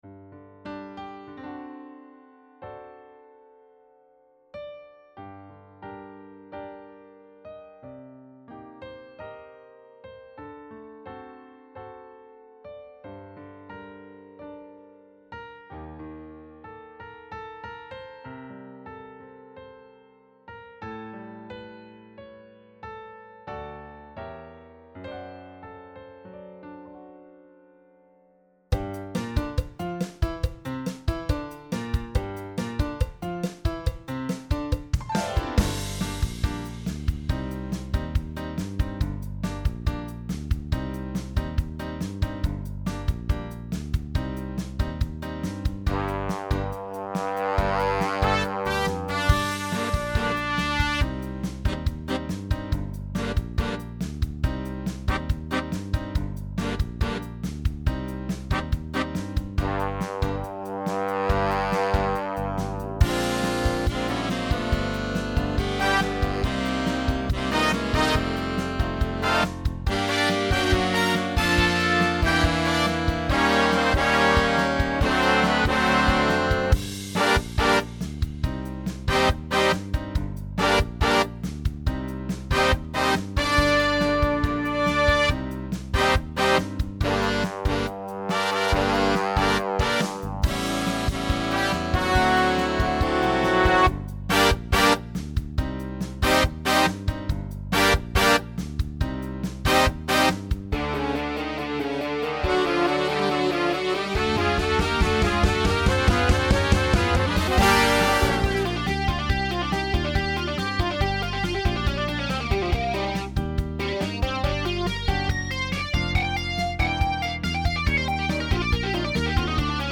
Latin Rock. Solo for Alto Sax.